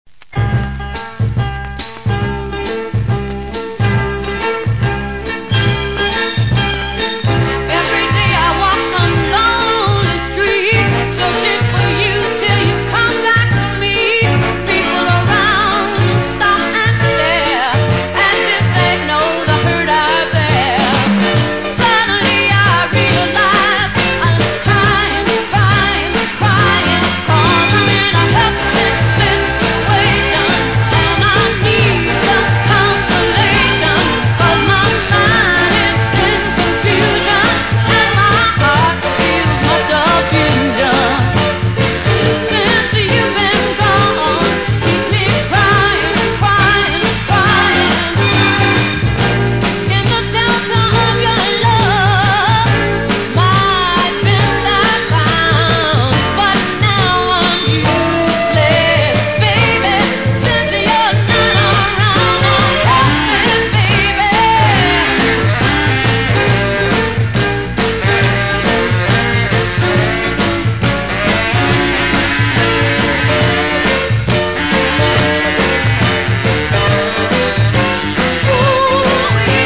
Marked but plays OK